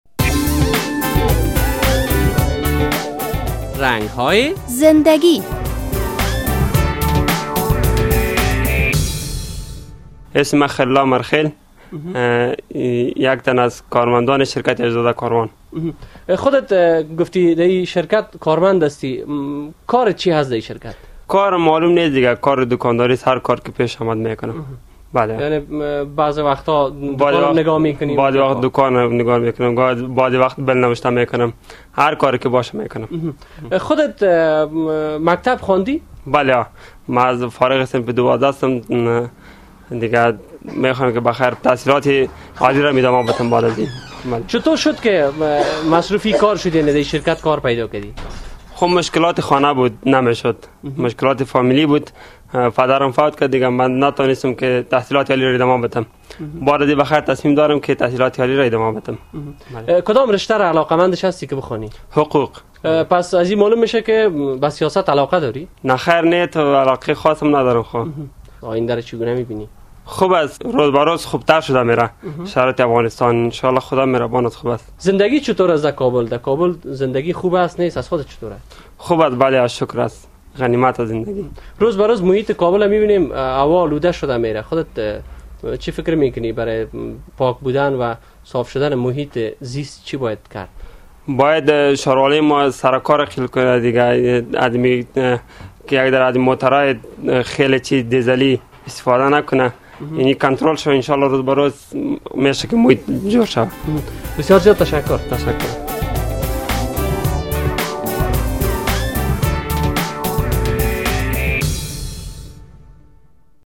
در این برنامهء رنگ های زنده گی با یک تن از کارمندان یک شرکت تجارتی صحبت شده است. شما می توانید این مصاحبه را با کلیک روی لینک زیر بشنویید:..